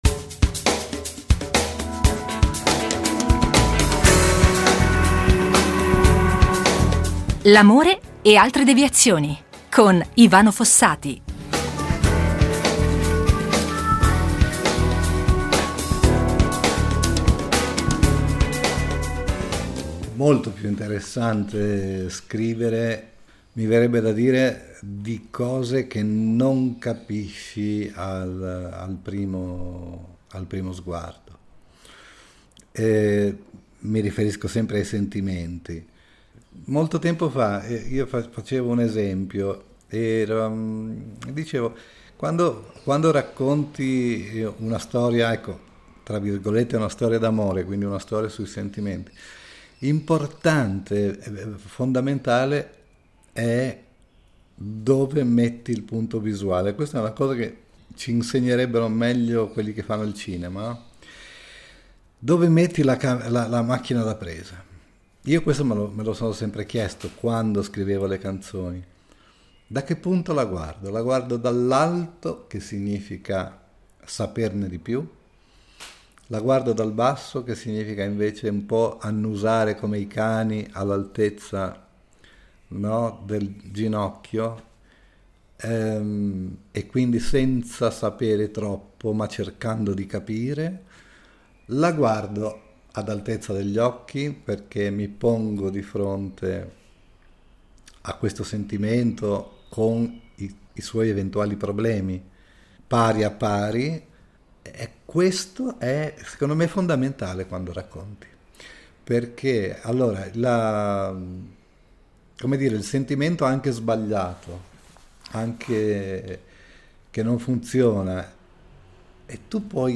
Con una prima settimana di eccezione: sarà proprio il cantautore Ivano Fossati a proporci la sua versione dell’amore.